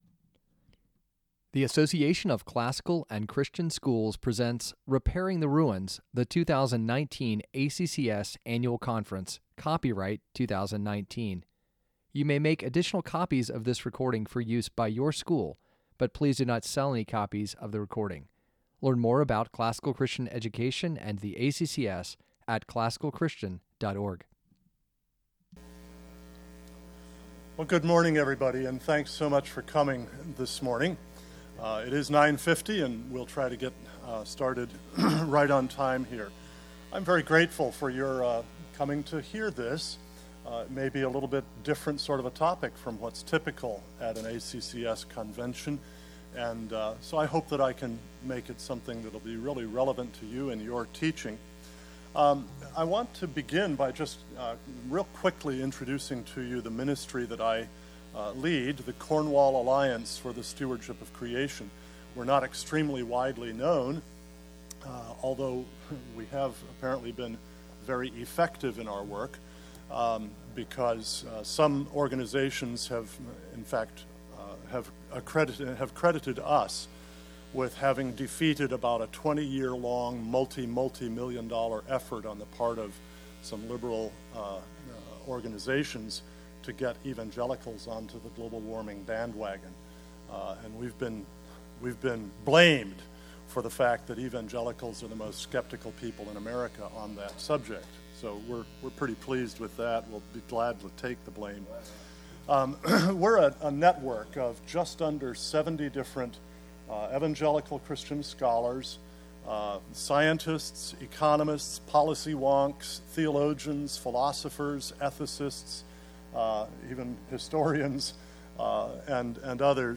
2019 Workshop Talk | 01:00:43 | All Grade Levels, Culture & Faith